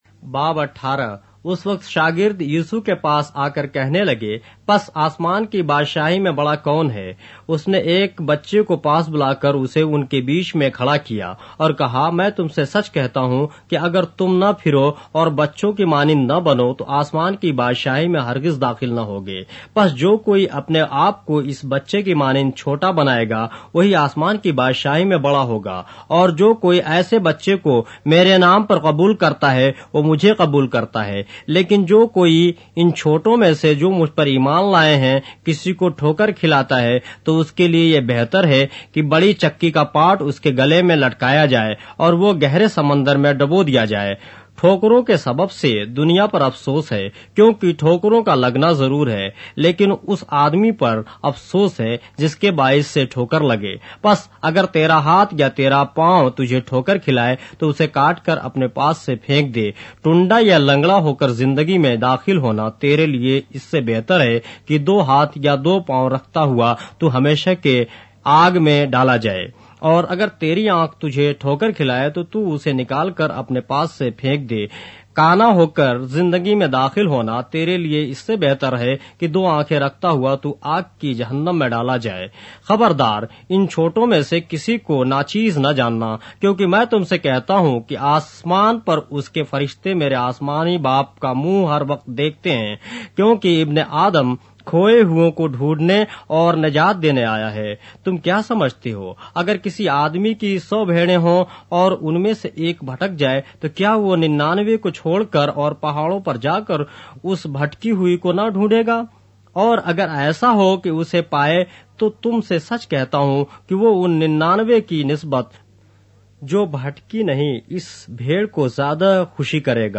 اردو بائبل کے باب - آڈیو روایت کے ساتھ - Matthew, chapter 18 of the Holy Bible in Urdu